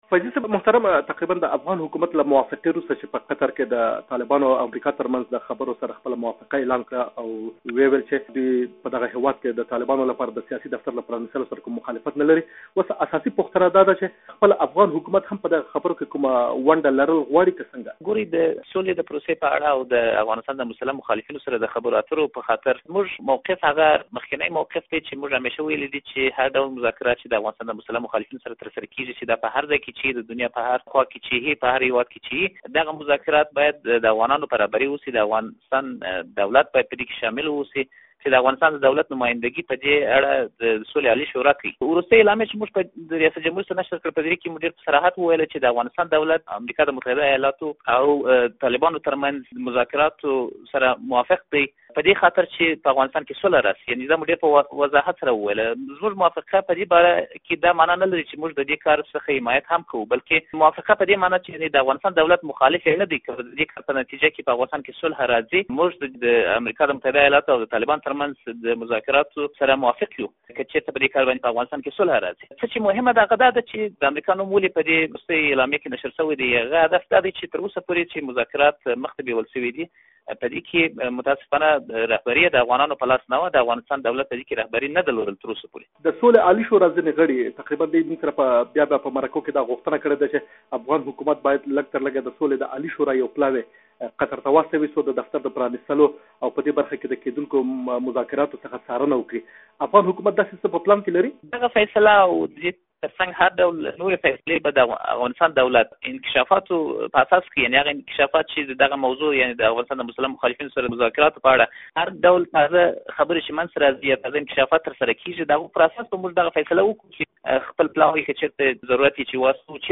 د ولسمشر کرزي ویاند ایمل فیضي سره مرکه